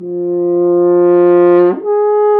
Index of /90_sSampleCDs/Roland L-CDX-03 Disk 2/BRS_F.Horn FX/BRS_Intervals
BRS F HRN 0Q.wav